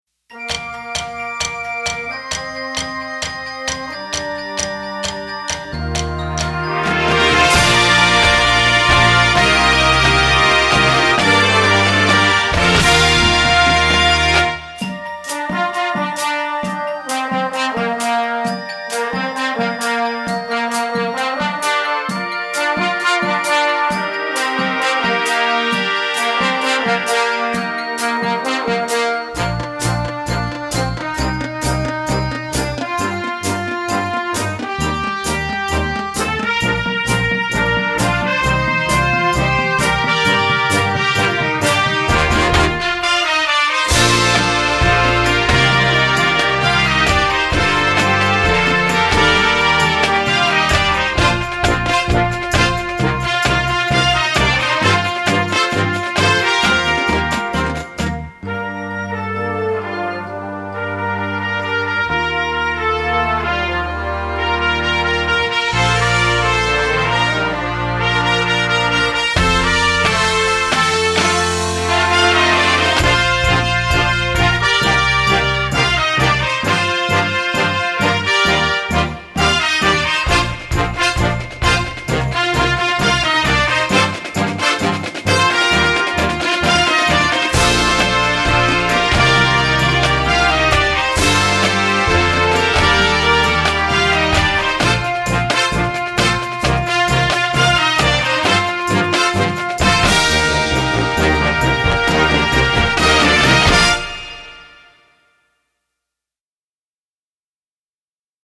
Gattung: Marching Band Series
Besetzung: Blasorchester